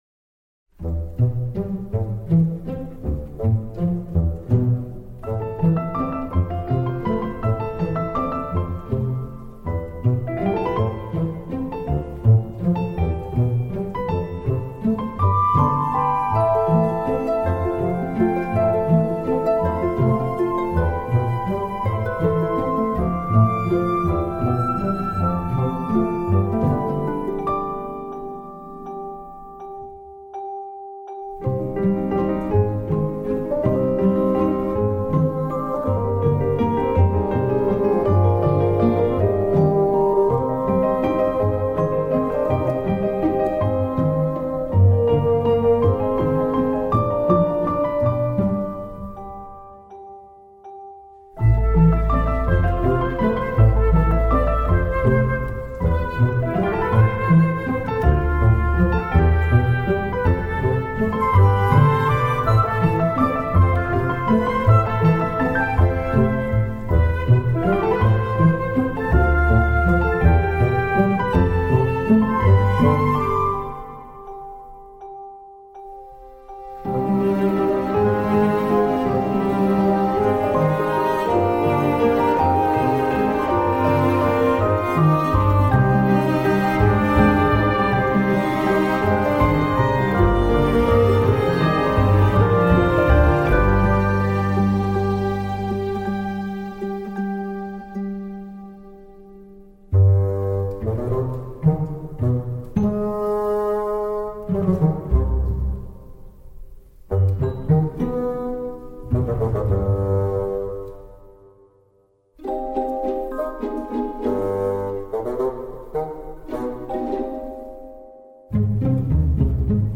piano, flûte, violoncelle, harpe
C’est beau, sensible… et lumineux.